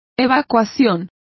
Complete with pronunciation of the translation of evacuations.